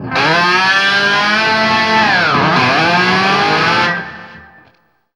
DIVEBOMB 8-L.wav